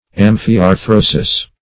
Search Result for " amphiarthrosis" : The Collaborative International Dictionary of English v.0.48: Amphiarthrosis \Am`phi*ar*thro"sis\, n. [NL., fr. Gr.
amphiarthrosis.mp3